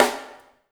LG SNR 1  -L.wav